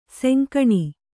♪ senkaṇi